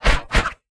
swing3.wav